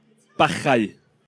Welsh pronunciation) is a small rural hamlet of about a dozen dwellings in Anglesey, North West Wales.